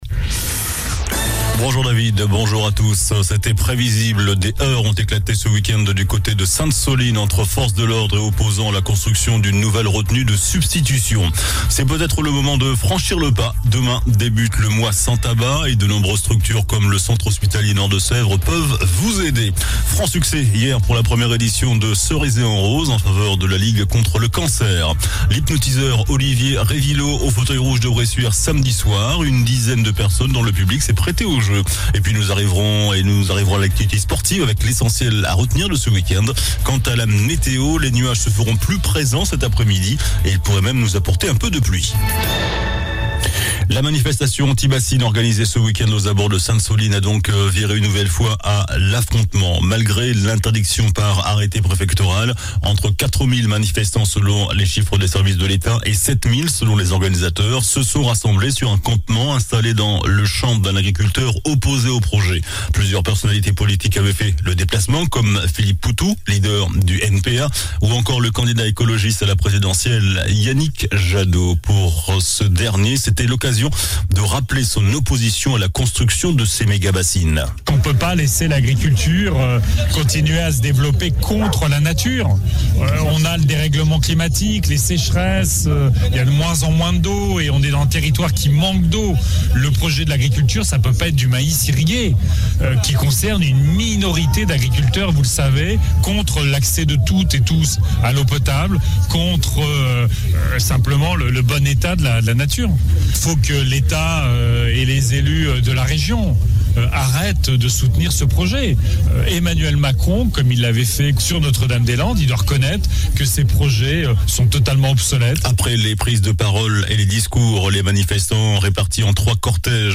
JOURNAL DU LUNDI 31 OCTOBRE ( MIDI )